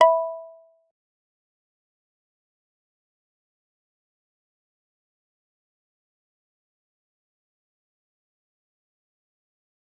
G_Kalimba-E6-mf.wav